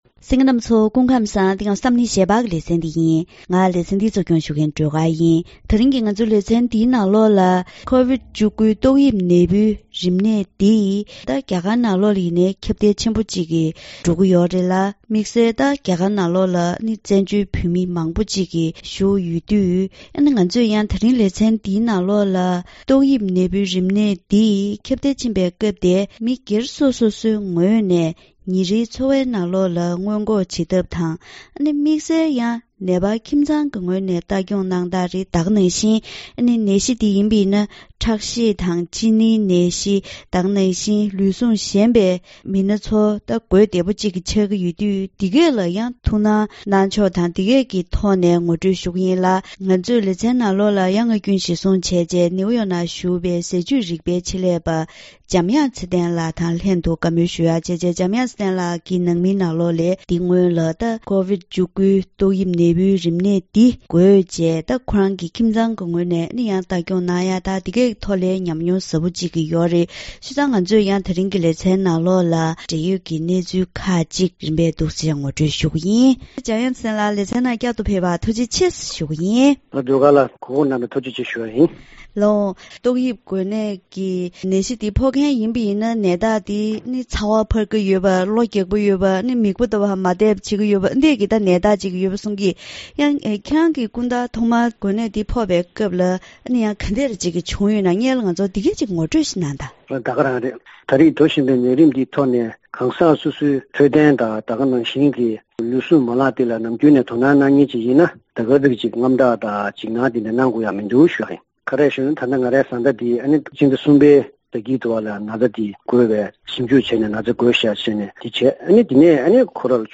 ད་རིང་གི་གཏམ་གླེང་ཞལ་པར་ལེ་ཚན་ནང་ཀོ་ཝིཌ་༡༩ཏོག་དབྱིབས་ནད་འབུའི་རིམས་ནད་འདི་ཁྱབ་གདལ་ཆེན་པོ་འགྲོ་བཞིན་པའི་སྐབས་འདིར་ཉིན་རེའི་འཚོ་བ་ནང་སྔོན་འགོག་བྱེད་ཐབས་དང་། དམིགས་བསལ་ལུས་ཟུངས་ཞན་པའི་མི་ཚོས་དོ་སྣང་ཇི་ལྟར་དགོས་པ་སོགས་ཀྱི་འཕྲོད་བསྟེན་དང་འབྲེལ་བའི་ཤེས་བྱ་ངོ་སྤྲོད་ཞུས་པ་ཞིག་ཡོད།